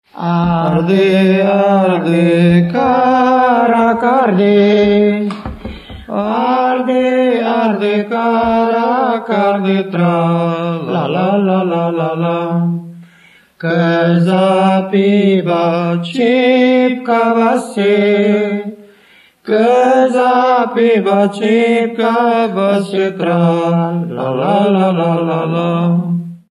Moldva és Bukovina - Moldva - Trunk
ének
Stílus: 7. Régies kisambitusú dallamok
Szótagszám: 8.8.7
Kadencia: b3 (2) 1